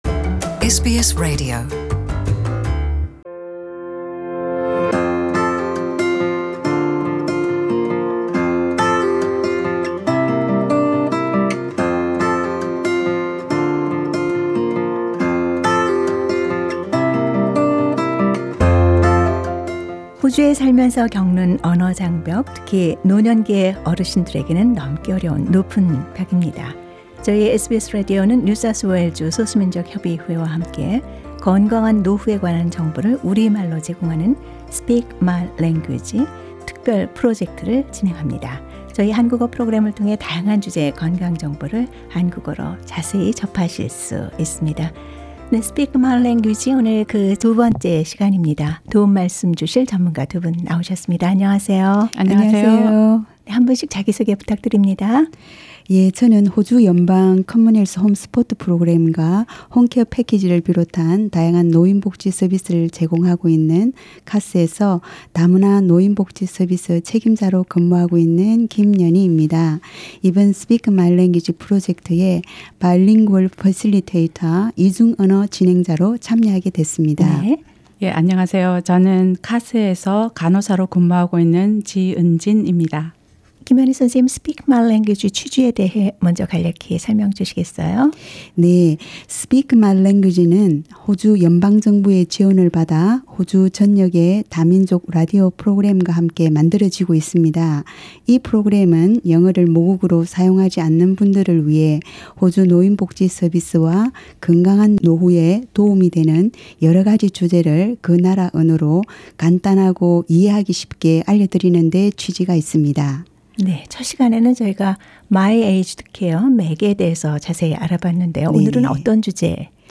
Speak My Language, conversations about ageing well Source: Getty Images